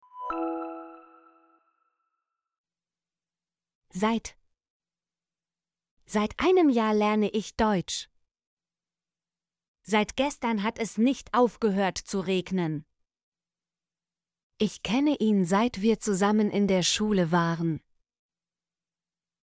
seit - Example Sentences & Pronunciation, German Frequency List